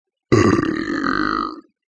Download Belch sound effect for free.
Belch